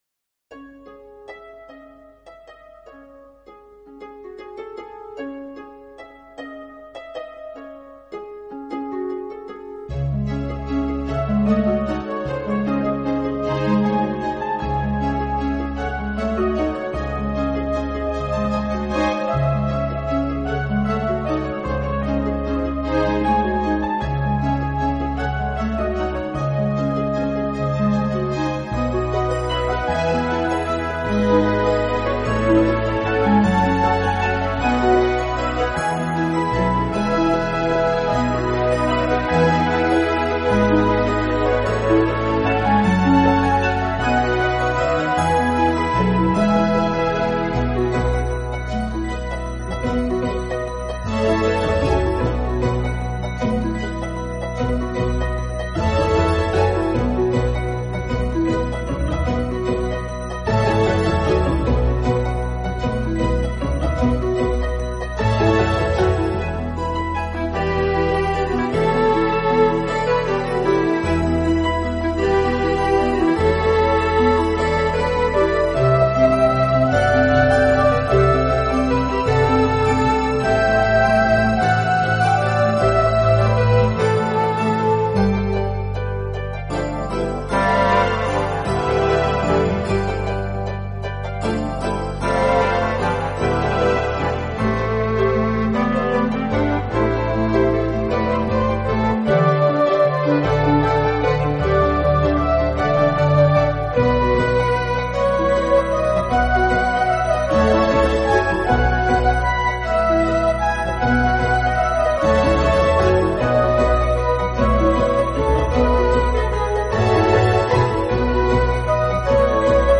新世纪音乐